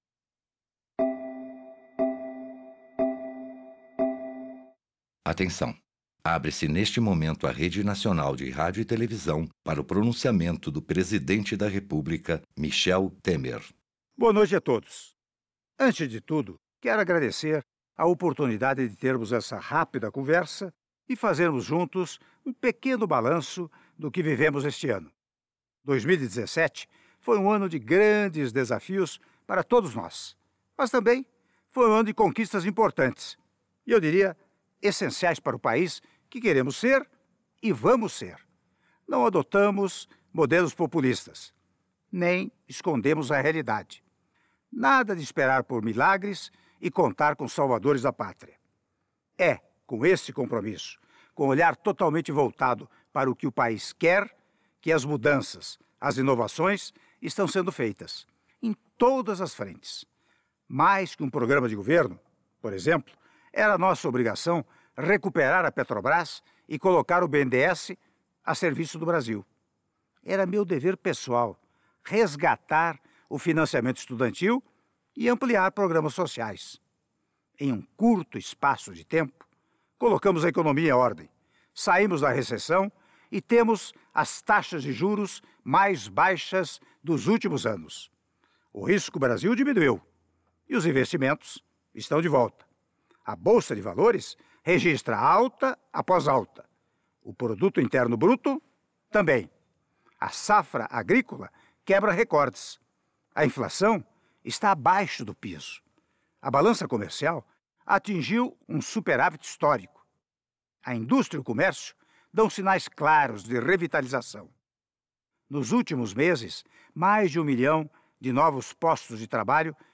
Pronunciamento do Prsidente Michel Temer